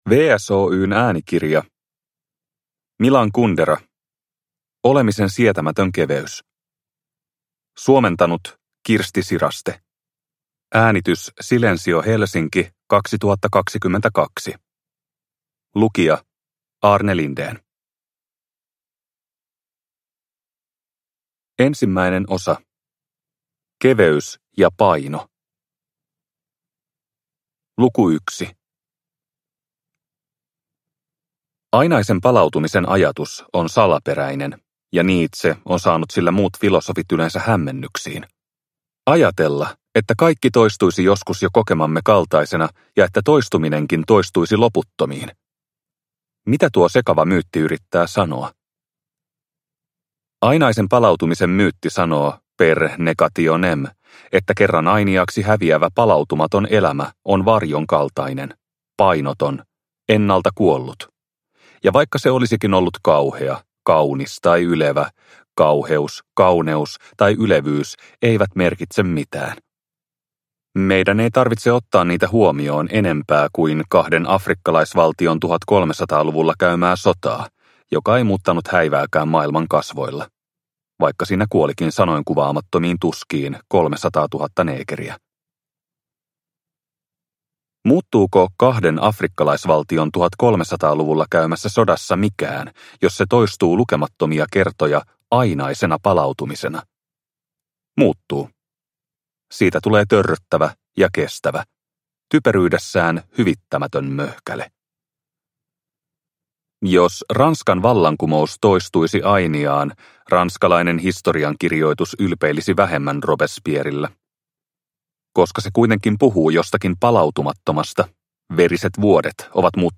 Olemisen sietämätön keveys – Ljudbok – Laddas ner